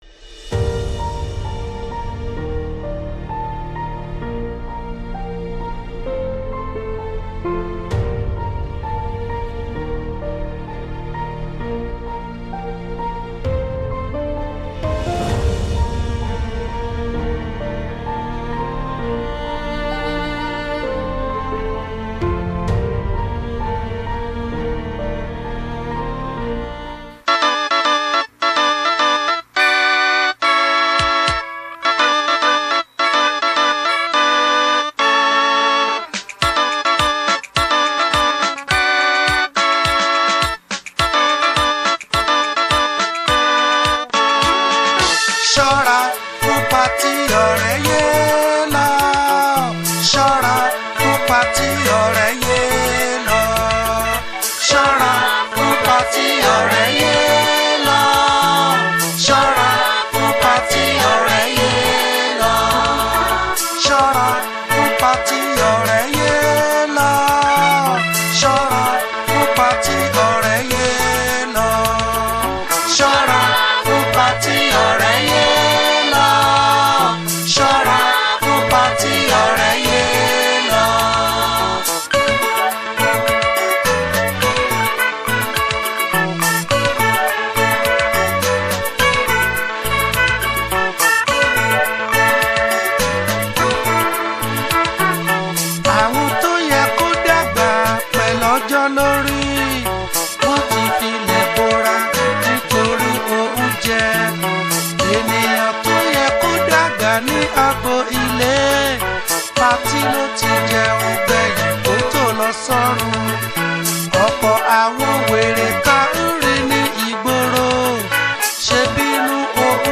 March 28, 2025 Publisher 01 Gospel 0